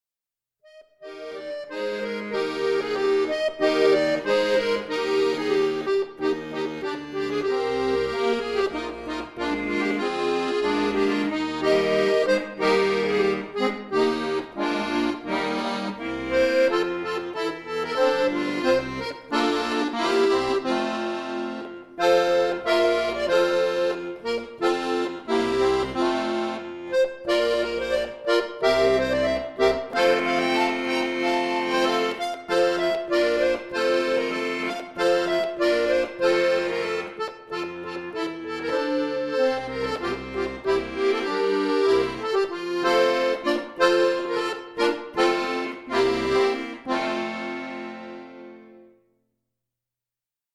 Akkordeon Solo , Weihnachtslied , Christmas Carol